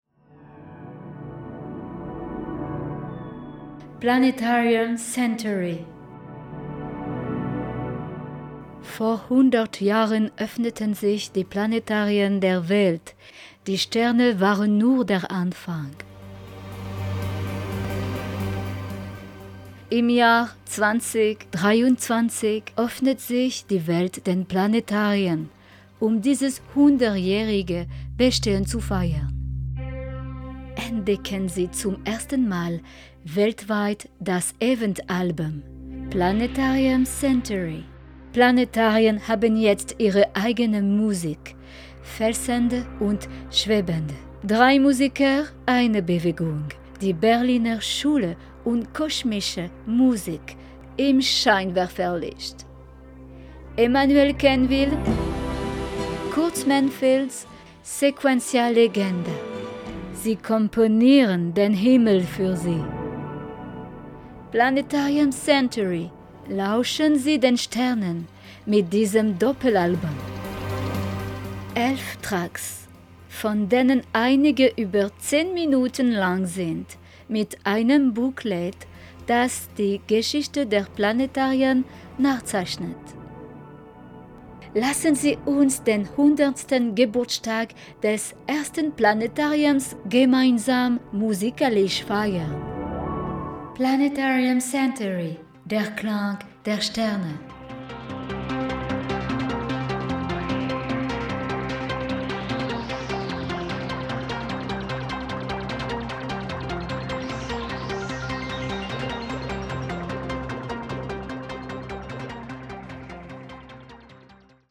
Pub_Fruitz_Deutsch